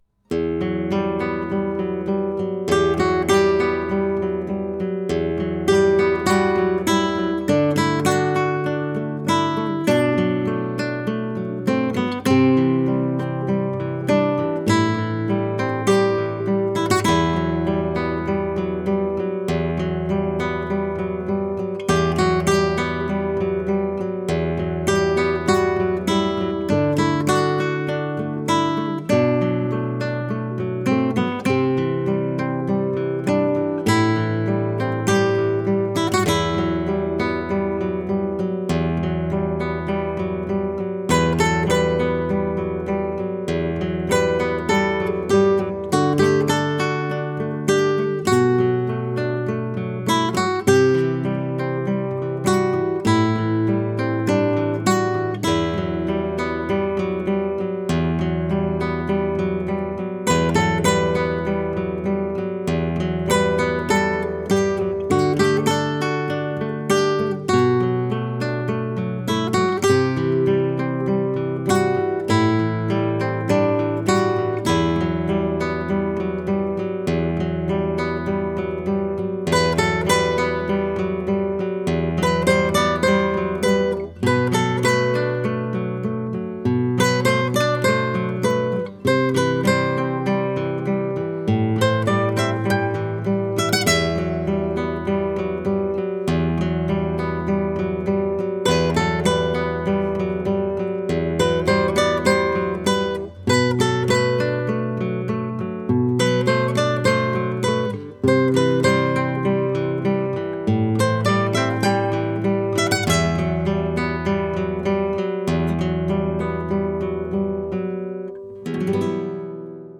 گام قطعهEm
متر قطعه4/4
این قطعه در متر چهار چهار و در گام Em نگارش شده.
برای گیتار